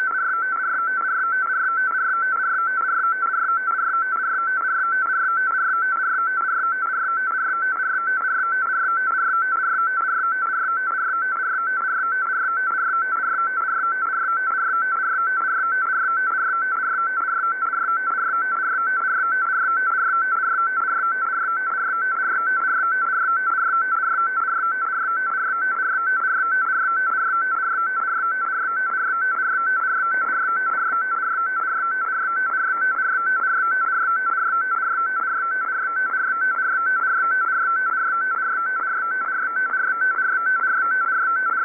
Начало » Записи » Радиоcигналы на опознание и анализ
FSK2, dF=170 Hz, V=100 bps